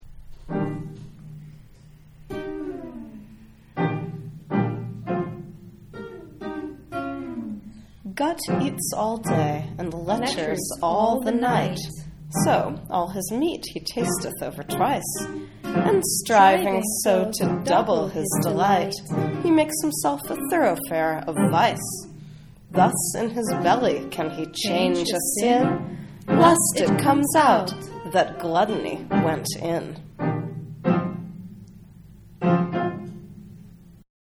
Our most multilingual poem-recording session ever. Unmastered, tracks just thrown together–but still sounding awesome.